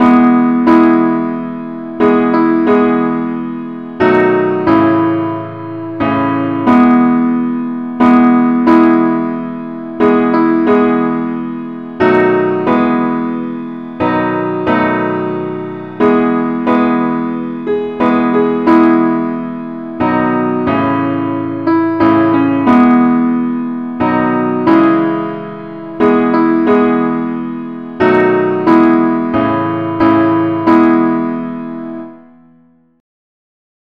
Meter: 8.6.8.6